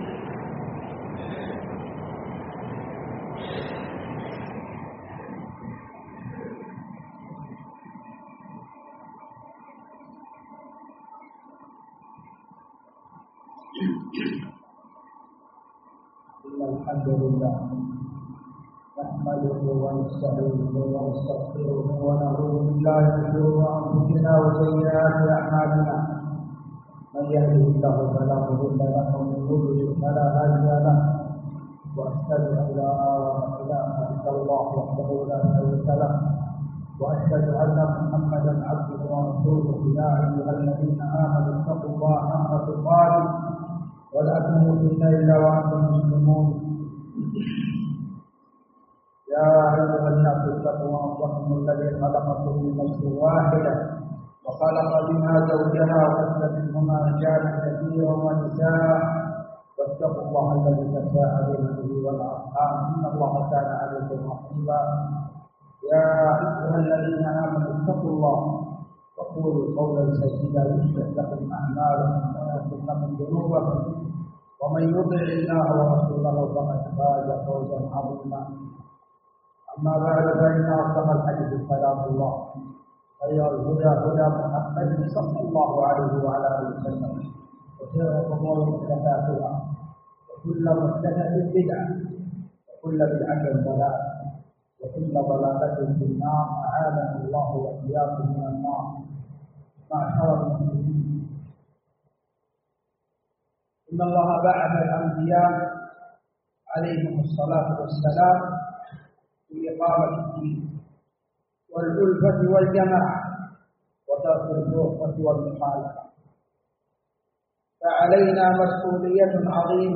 جامع الملك عبدالعزيز باسكان الخارش بصامطة
مواعظ ورقائق